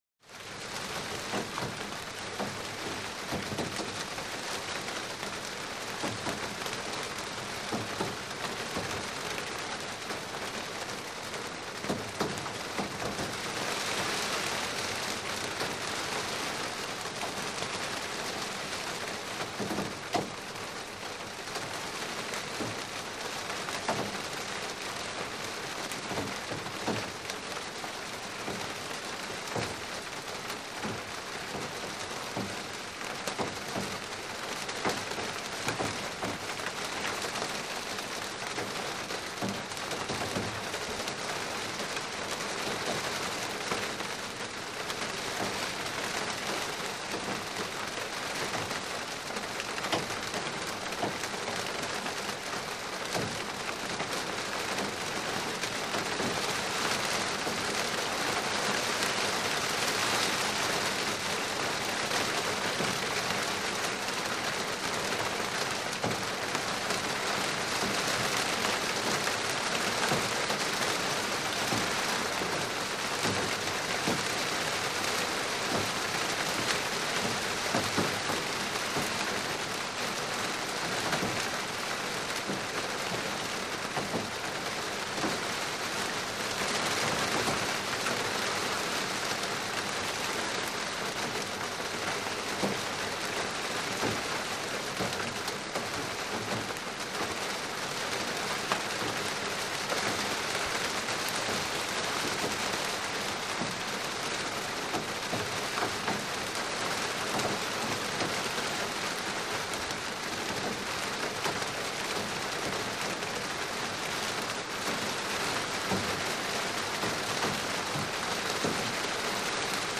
Rain; Splatty, Falls With Some Swells On A Tin Roof.